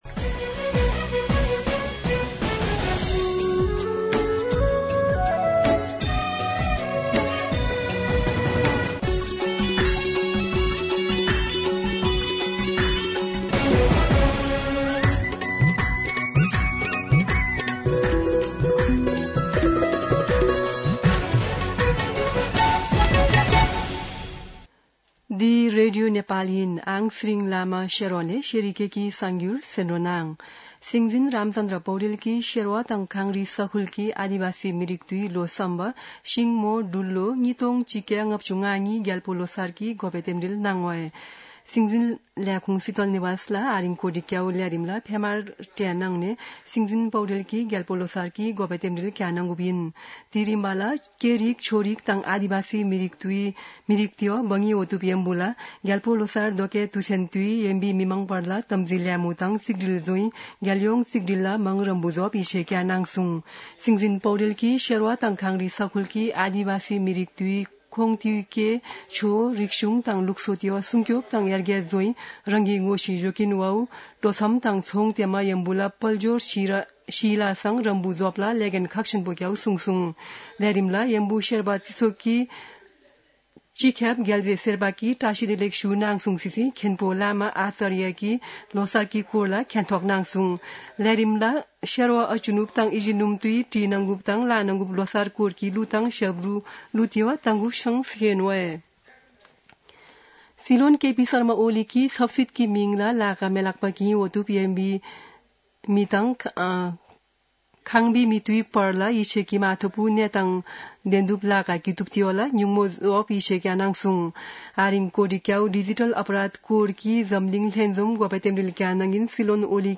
शेर्पा भाषाको समाचार : १६ फागुन , २०८१
Sherpa-News-11-15.mp3